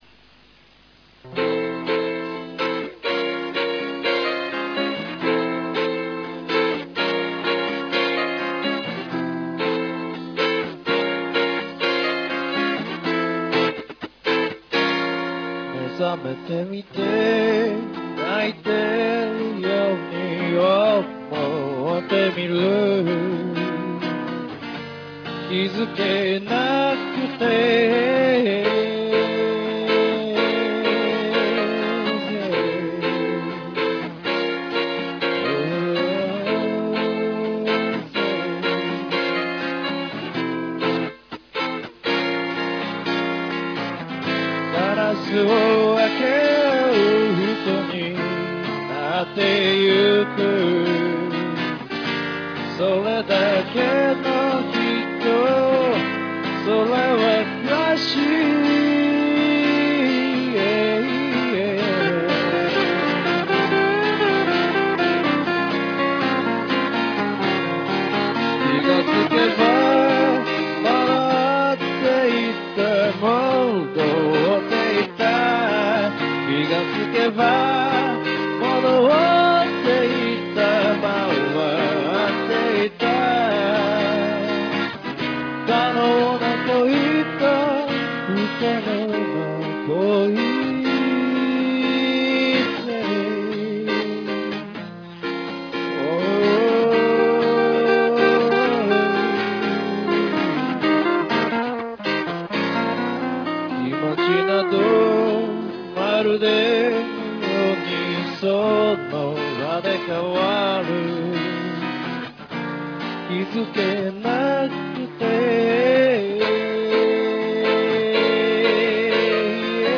この曲はボサ＋フォークを狙って作った曲です。